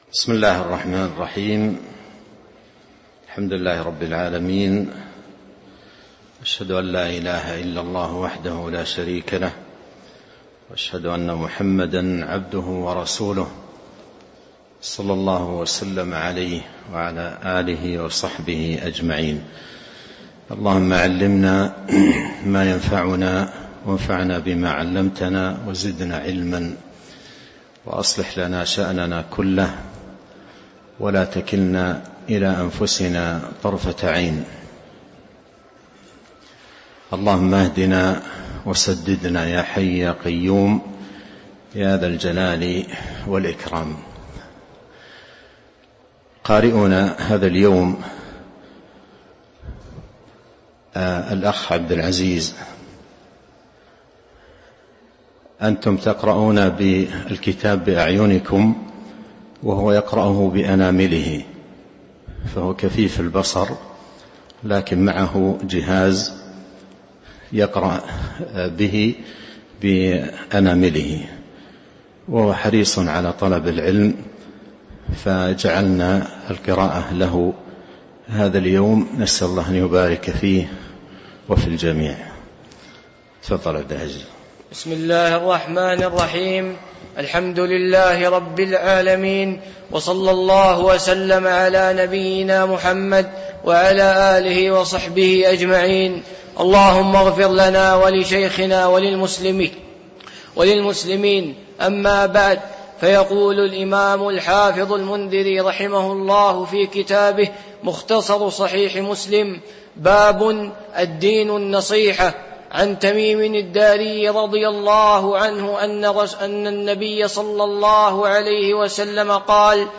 تاريخ النشر ٢٣ جمادى الأولى ١٤٤٣ هـ المكان: المسجد النبوي الشيخ: فضيلة الشيخ عبد الرزاق بن عبد المحسن البدر فضيلة الشيخ عبد الرزاق بن عبد المحسن البدر باب الدين النصيحة (06)